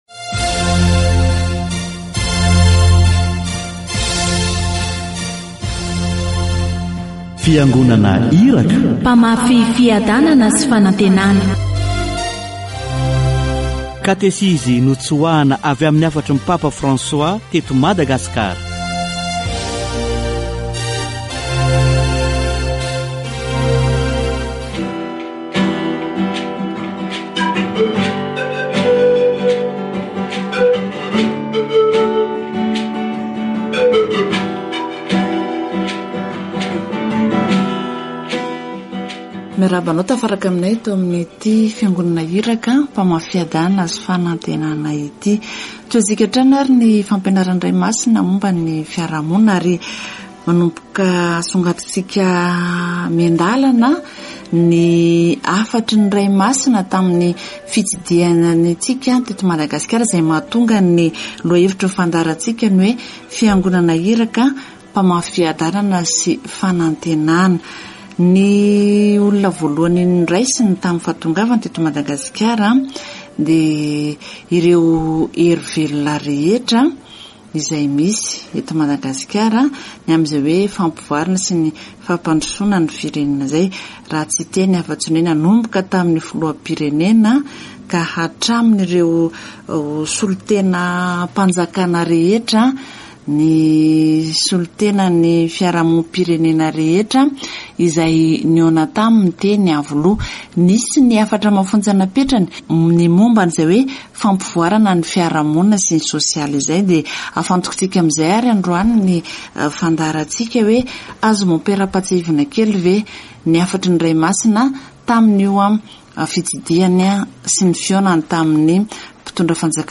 Category: Deepening faith
Catechesis on the visit of Pope Francis to Madagascar